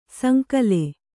♪ sankale